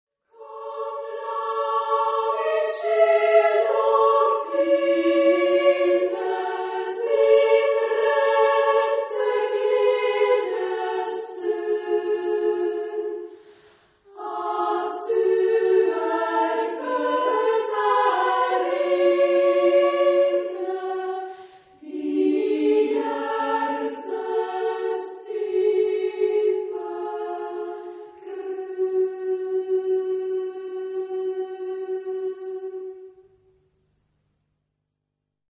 Género/Estilo/Forma: Sagrado ; Profano ; Canción de Navidad ; Homófono
Carácter de la pieza : melodioso ; cantabile ; alegre
Tipo de formación coral: SA  (2 voces Coro infantil O Coro femenino )
Tonalidad : sol menor